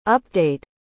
Como pronunciar update?
A pronúncia correta é /ˈʌp.deɪt/, com o som de “up” no início e “date” em seguida.